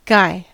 Ääntäminen
US : IPA : [ɡaɪ]